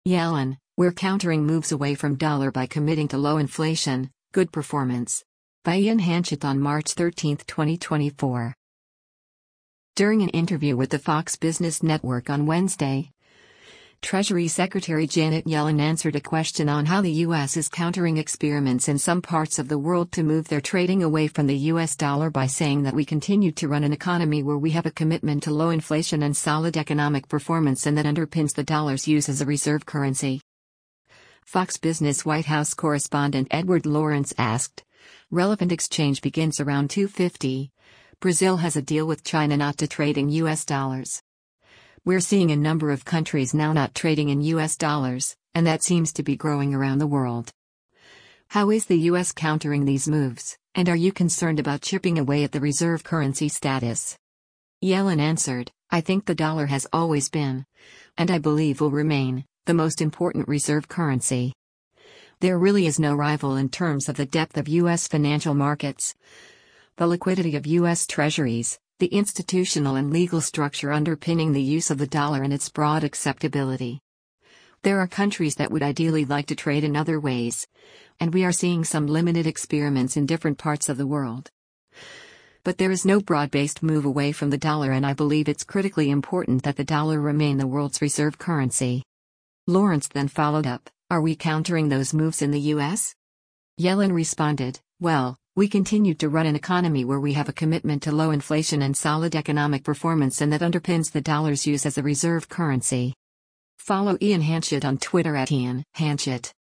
During an interview with the Fox Business Network on Wednesday, Treasury Secretary Janet Yellen answered a question on how the U.S. is countering experiments in some parts of the world to move their trading away from the U.S. dollar by saying that “we continue to run an economy where we have a commitment to low inflation and solid economic performance and that underpins the dollar’s use as a reserve currency.”